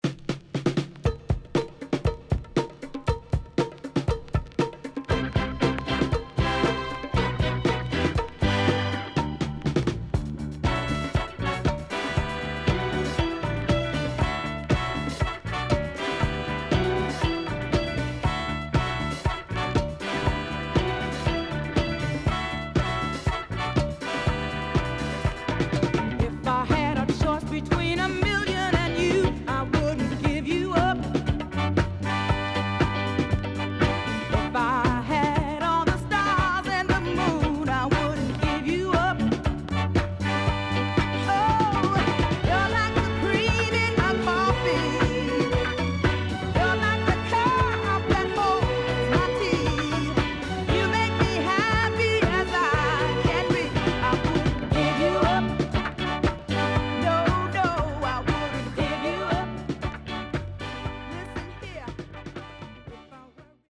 HOME > SOUL / OTHERS
SIDE B:所々チリノイズがあり、少しプチノイズ入ります。